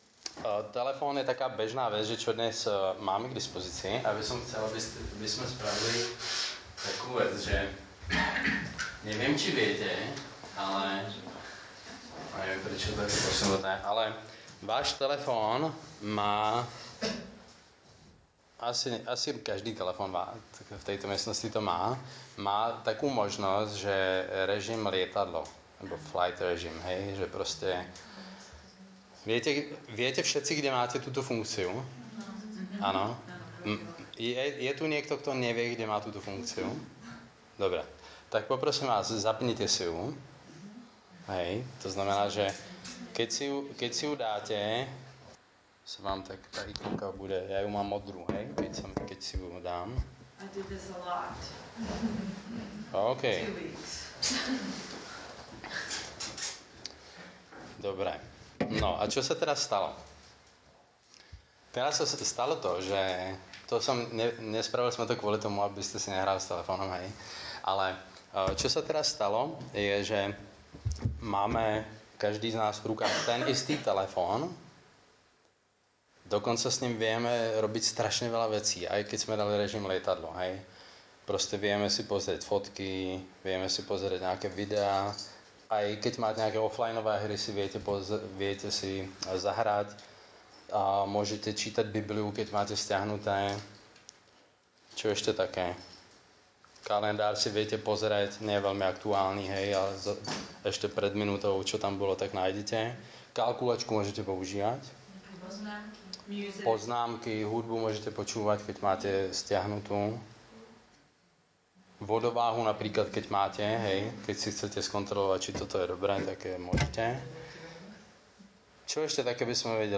Nahrávka kázne Kresťanského centra Nový začiatok z 27. novembra 2016